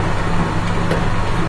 motor.ogg